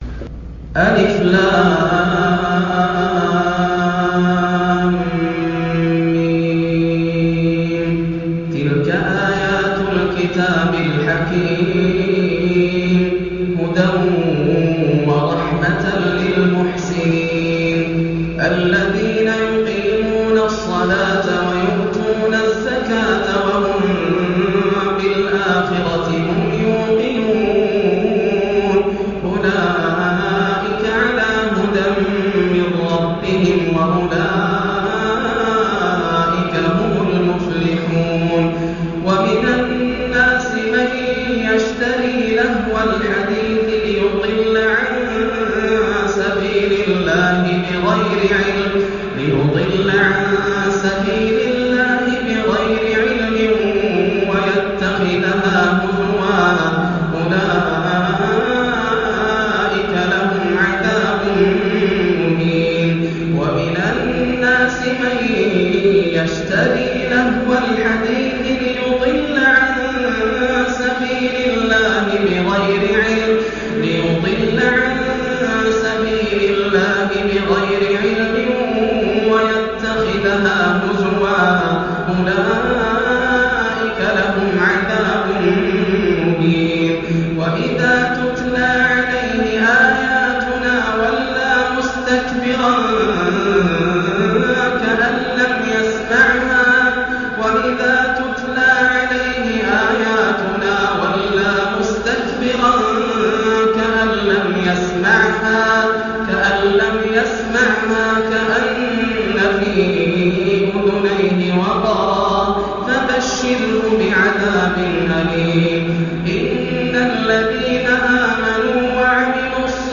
سورة لقمان > السور المكتملة > رمضان 1430هـ > التراويح - تلاوات ياسر الدوسري